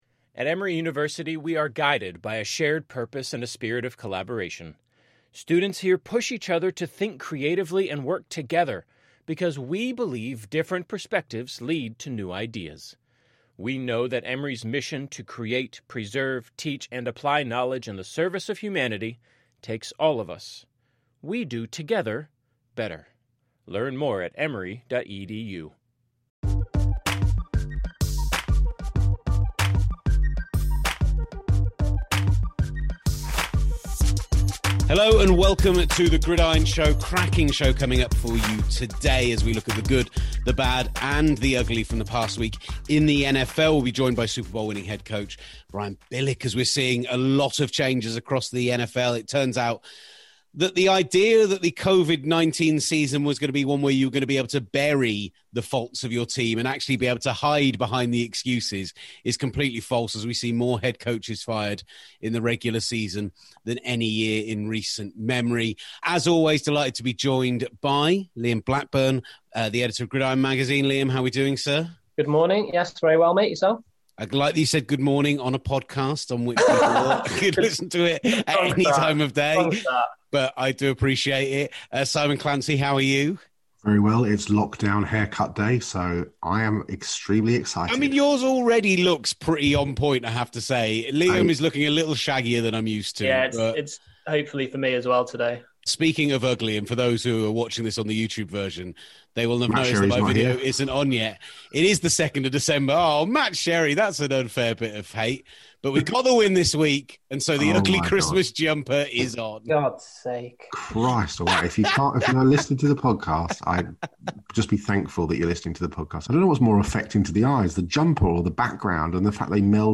Plus Super Bowl winning head coach Brian Billick picks his choice of the best coaching jobs for the 2021 season. And there's an appearance by the postman!